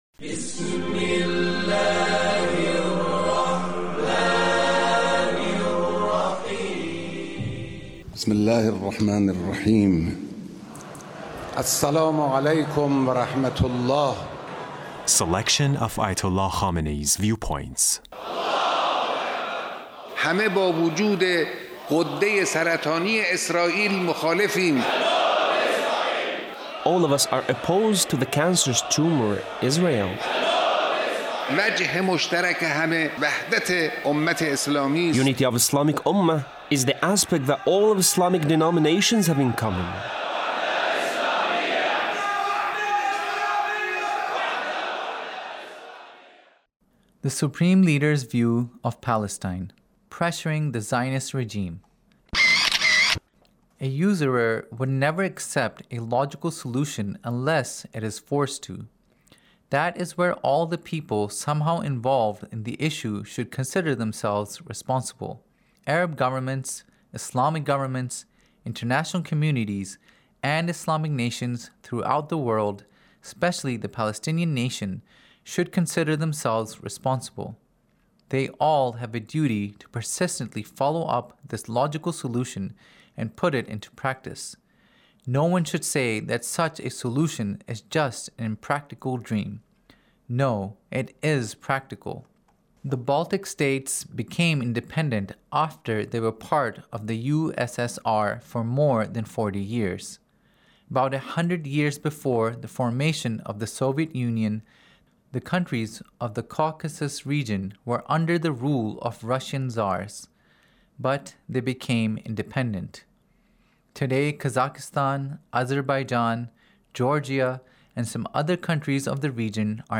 Leader's Speech (1871)
Leader's Speech on Palestine